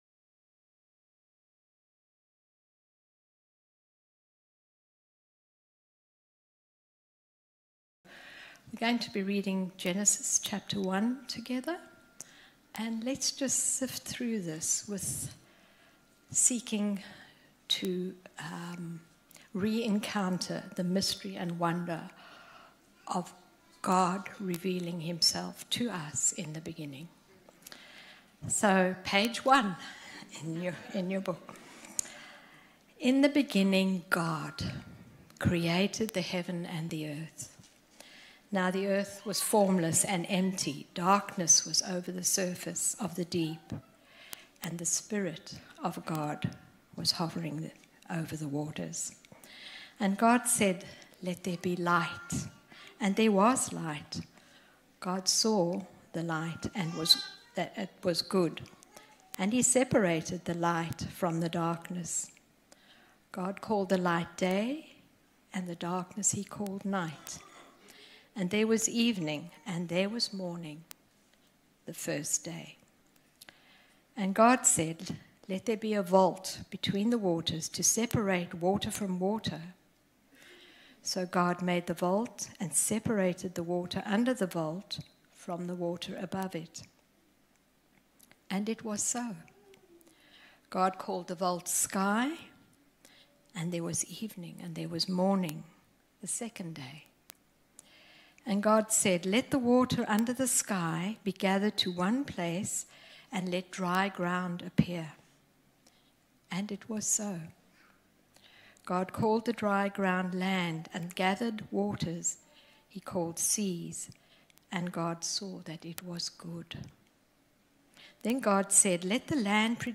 This morning we read through Genesis 1, where God creates the world in six days and declares it good.